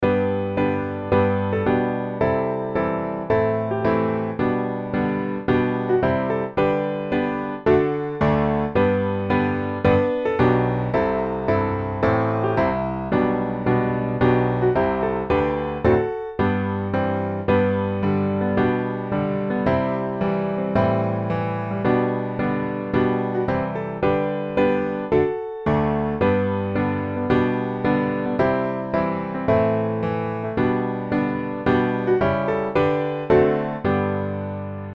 The simple, hearty joy of this song is refreshing.
G Majeur